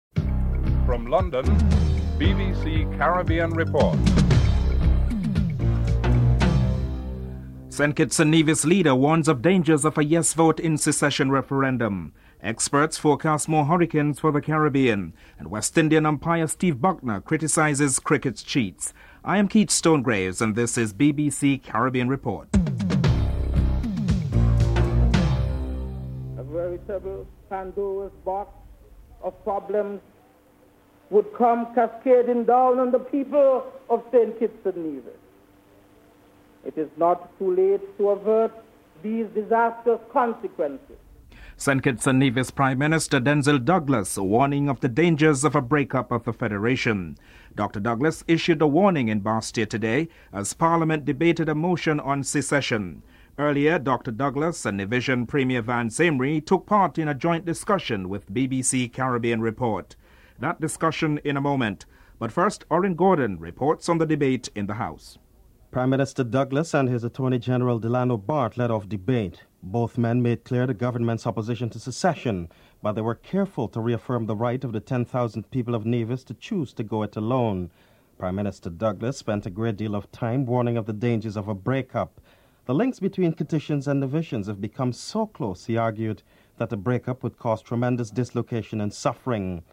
1. Headlines (00:00-00:25)
Dr Denzil Douglas and Premier Vance Amlory are interviewed.
Umpire Steve Bucknor is interviewed (11:46-13:23)